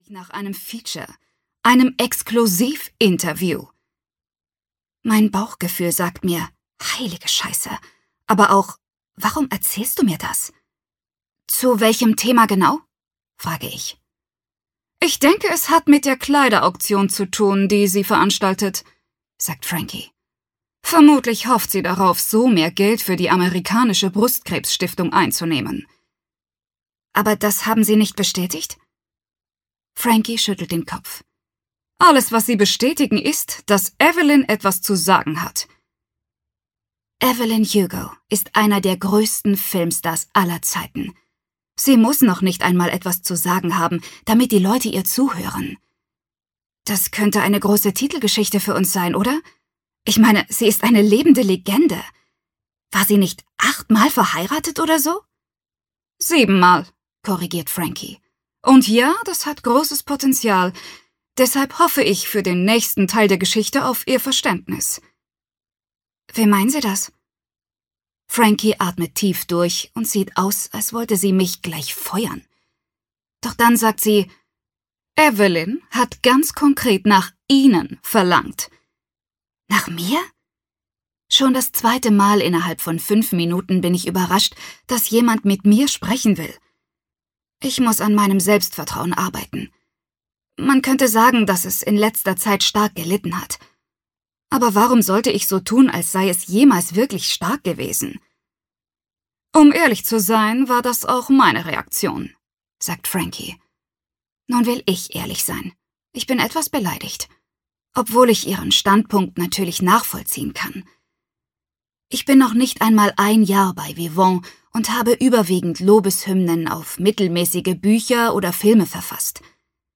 Die sieben Männer der Evelyn Hugo (DE) audiokniha
Ukázka z knihy